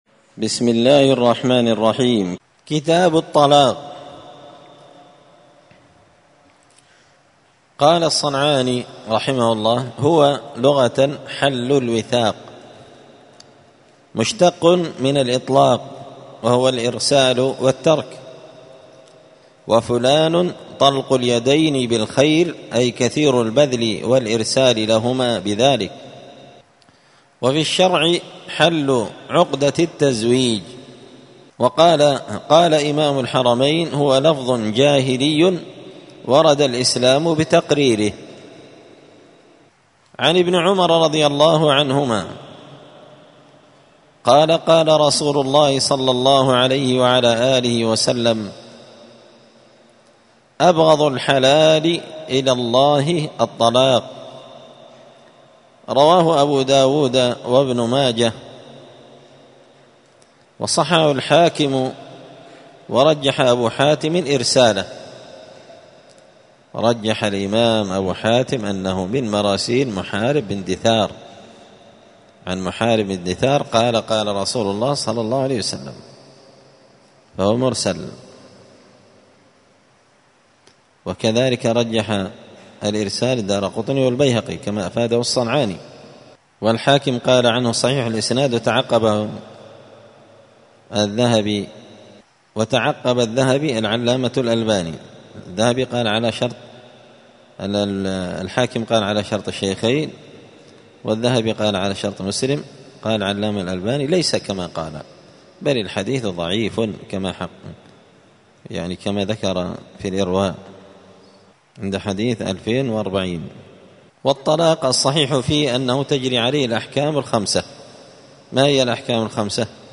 *الدرس الأول (1) {تعريف الطلاق}*